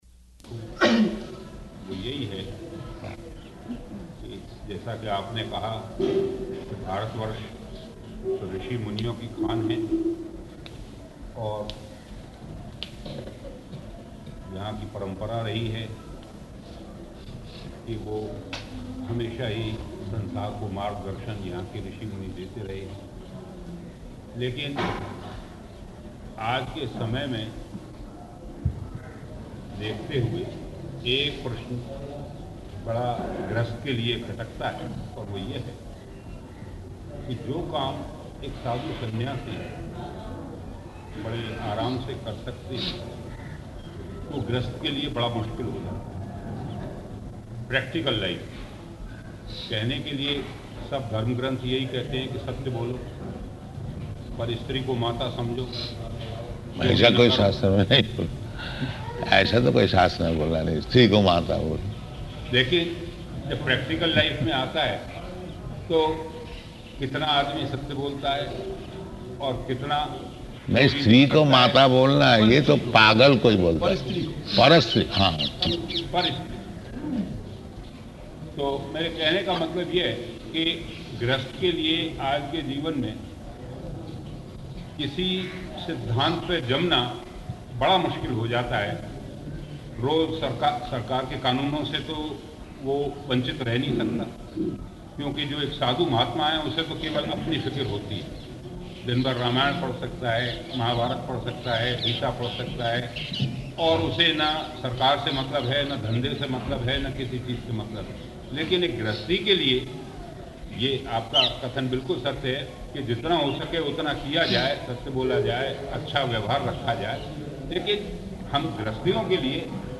Room Conversation
Type: Conversation